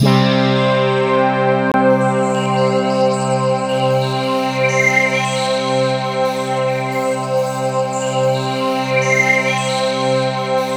BRASSPADC3-L.wav